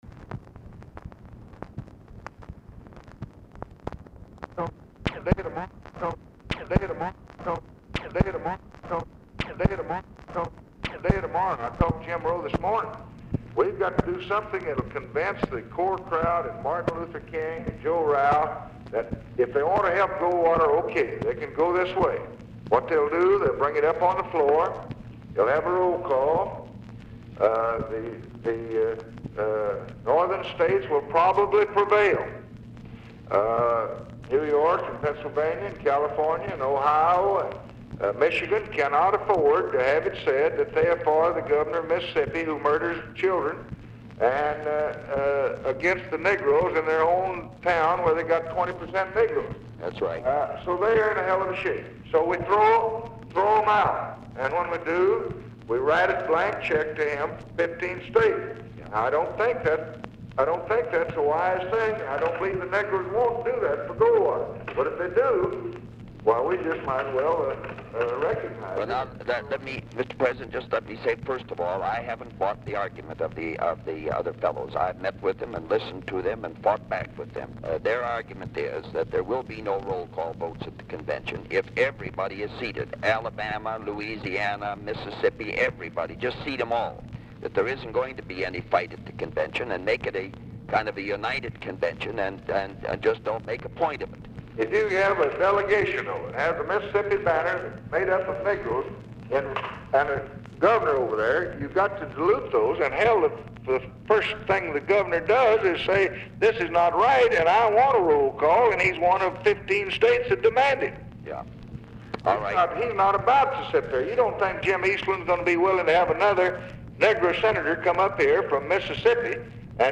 Telephone conversation # 4918, sound recording, LBJ and HUBERT HUMPHREY, 8/14/1964, 11:05AM | Discover LBJ
Format Dictation belt
Location Of Speaker 1 Oval Office or unknown location
Specific Item Type Telephone conversation